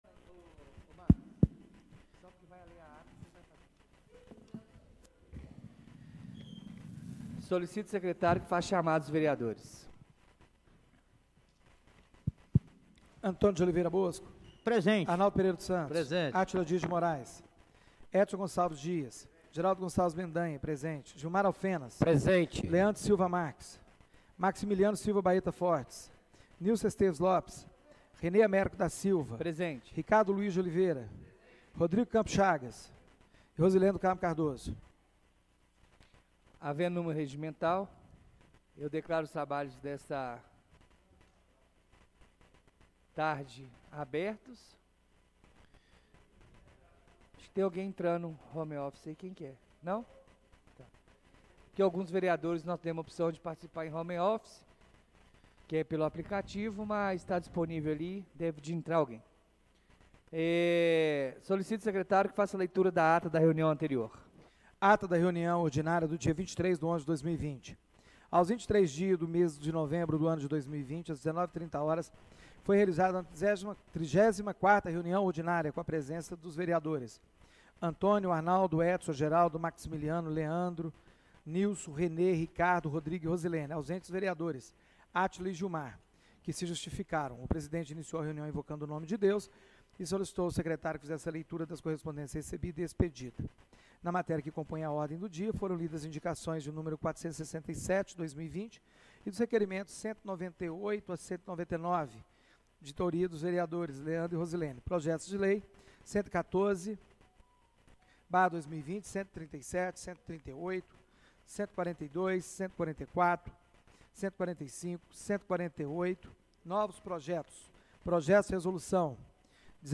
Reunião Extraordinária do dia 02/12/2020